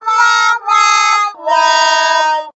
target_Lose.ogg